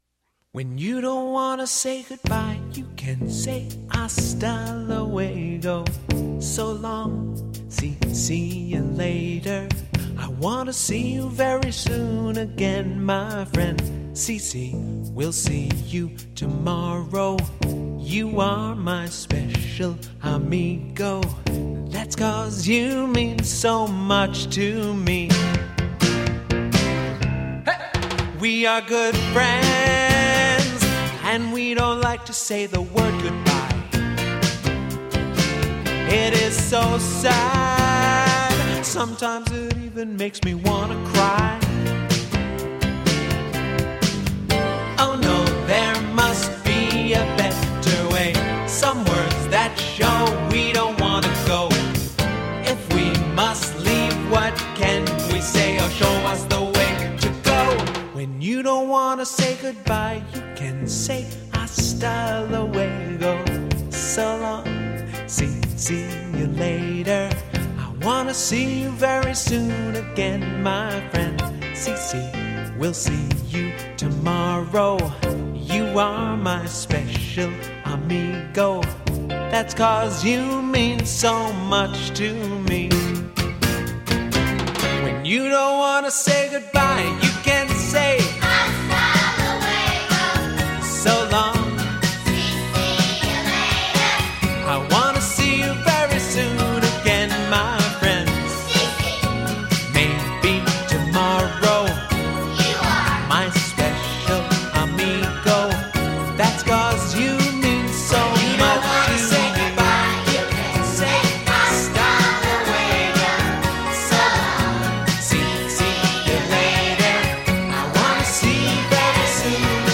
fun, upbeat goodbye song
Download Vocal Track (Free MP3)